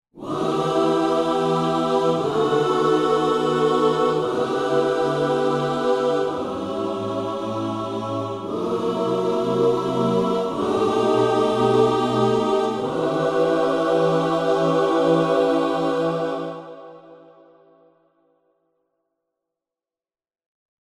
Gospel Whoos demo =1-B02.mp3